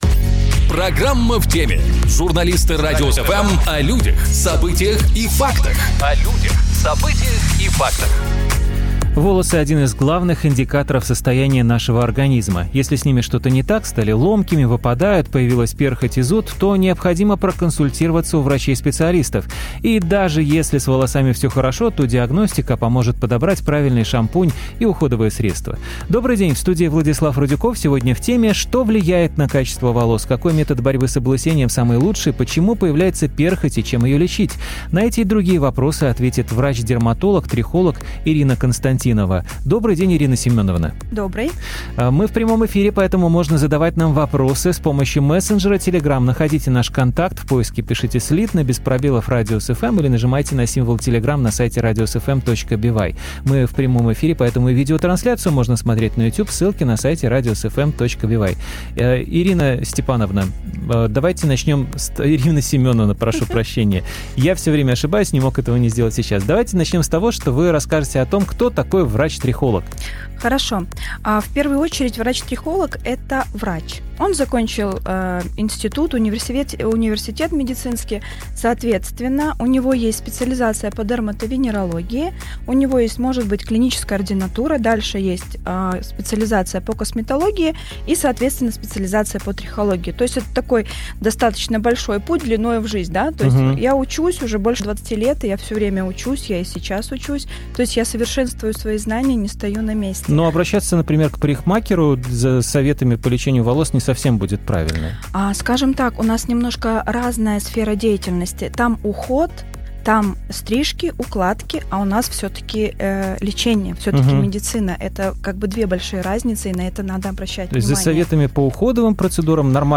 Трихолог отвечает на вопросы о волосах